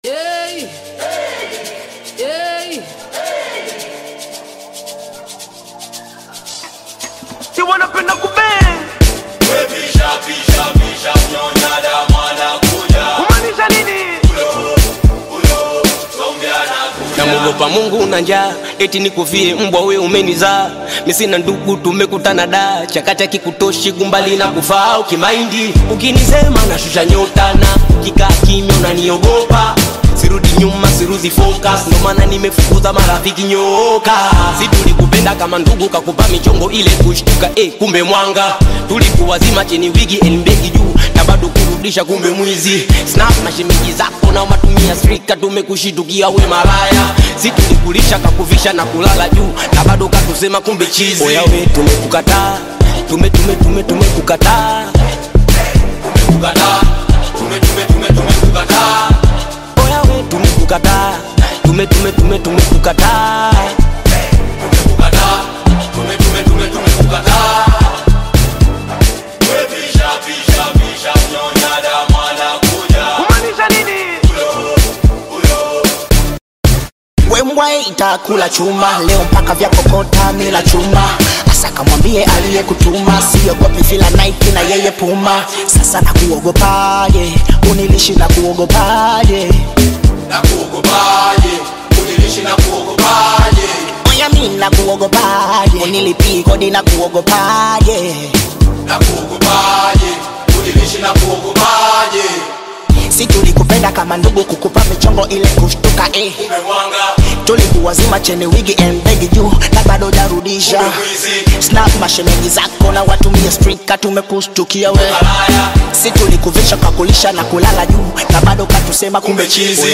Tanzanian Bongo Flava
Bongo Flava